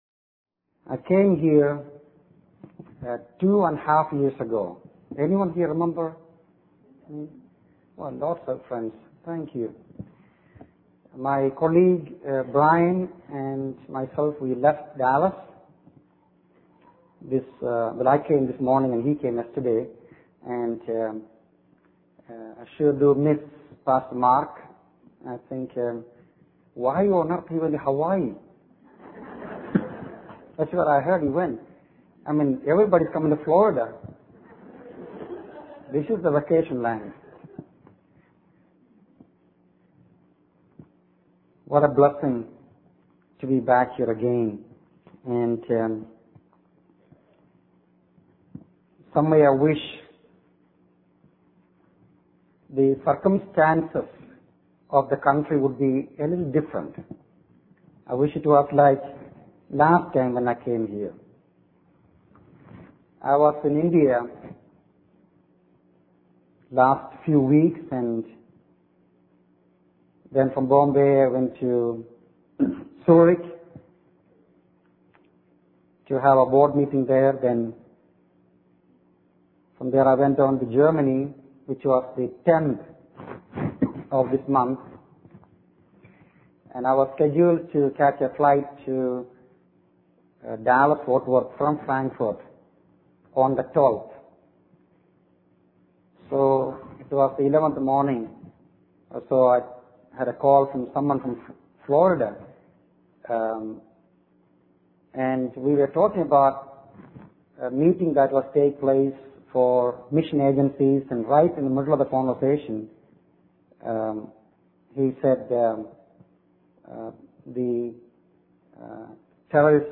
In this sermon, the speaker reflects on his own life and ministry, realizing that he has become complacent and lacking in passion for sharing the gospel. He is convicted by the fact that thousands of people die every day without hearing about Jesus.